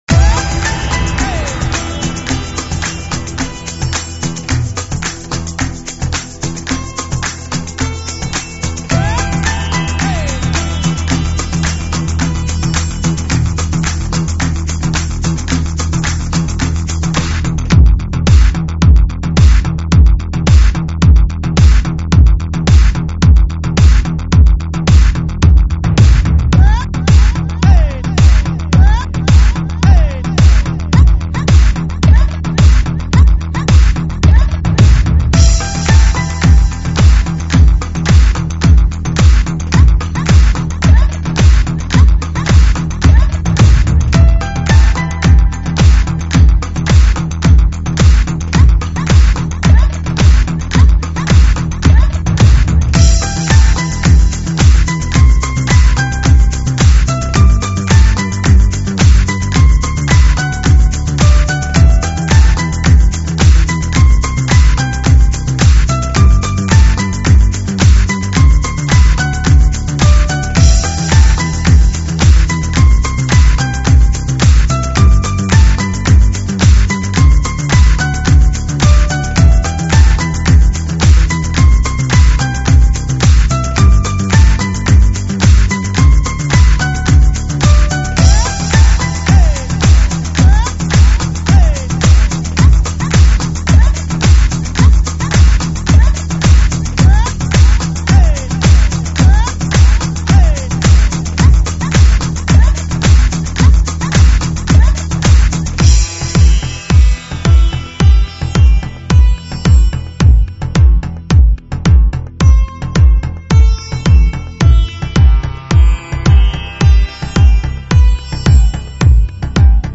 [14/12/2008]超级重低音 ----让你嗨到死 激动社区，陪你一起慢慢变老！